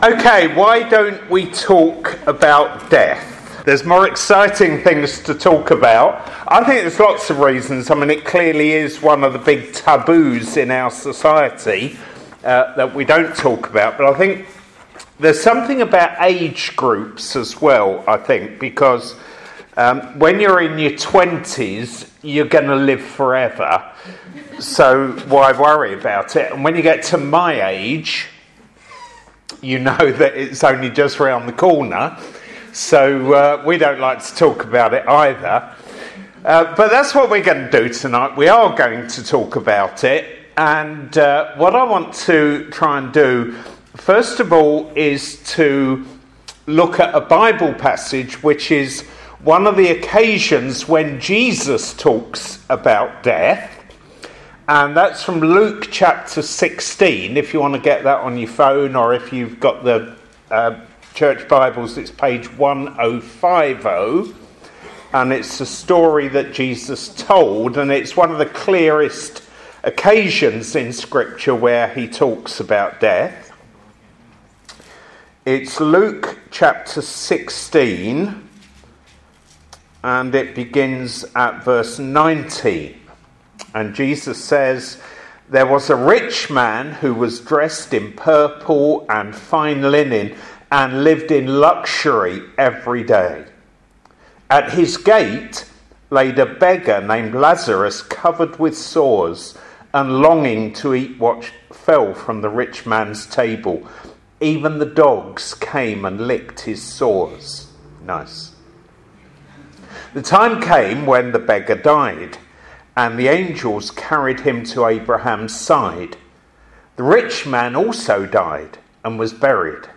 A sermon preached at St Swithin’s Lincoln on April 7th 2019